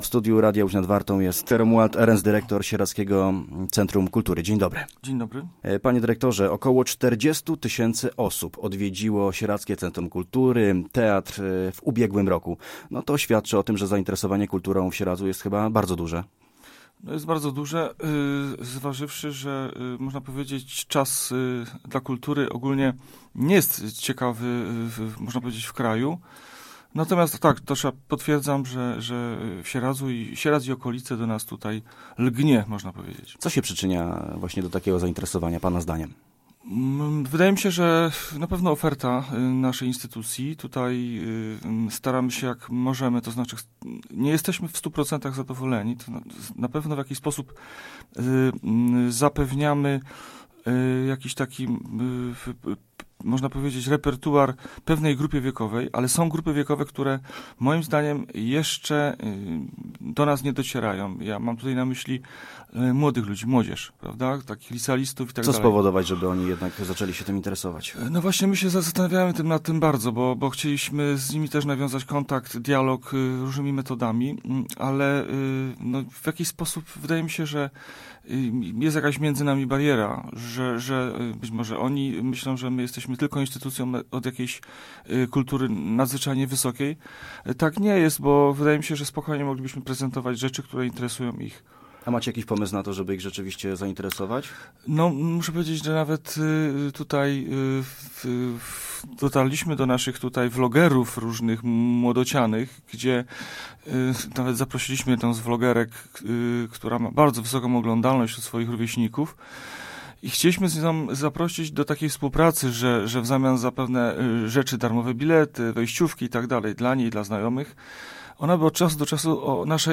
Posłuchaj całej rozmowy: Nazwa Plik Autor – brak tytułu – audio (m4a) audio (oga) Warto przeczytać Pieniądze na sport w województwie łódzkim. 12 obiektów przejdzie remont 9 lipca 2025 Niż genueński w Łódzkiem.